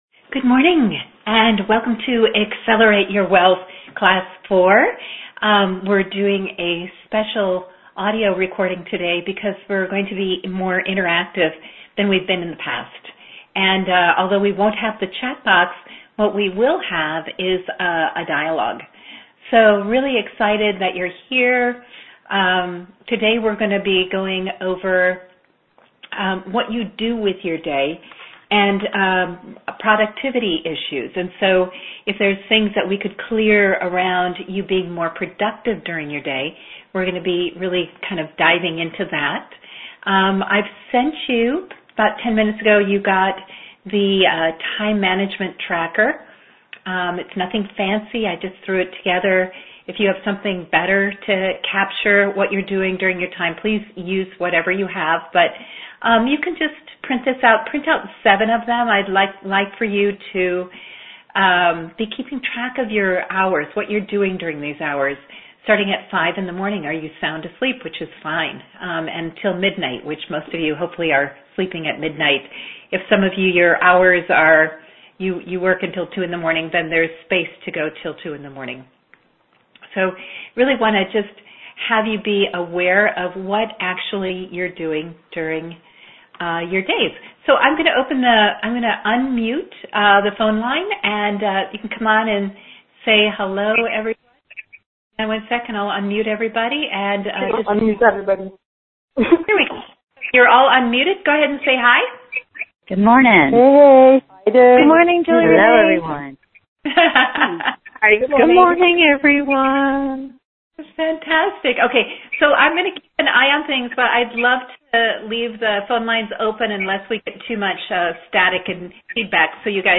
November 2, 2015 Live Session:
Accelerate+Wealth,+Class+4,+Nov+2015.mp3